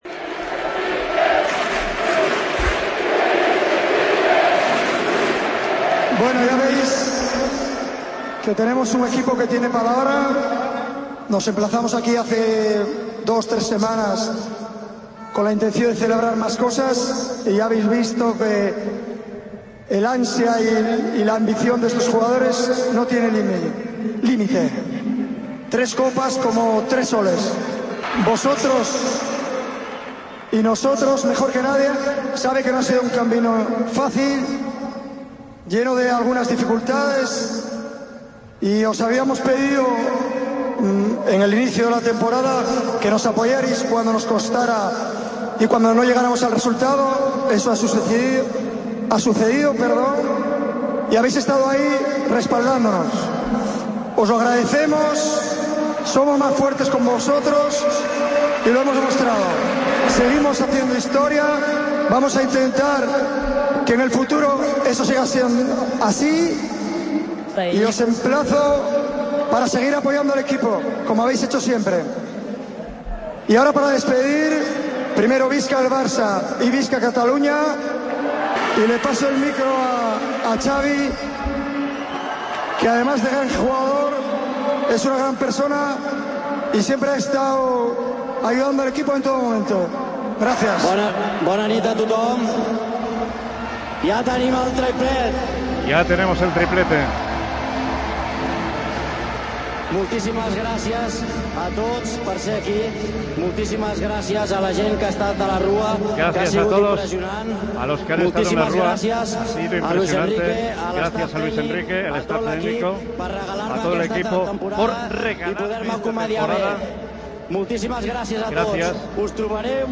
Luis Enrique, Xavi, Iniesta, Messi, un emocionado Alves y un provocativo Piqué celebran la 'Quinta' con el Camp Nou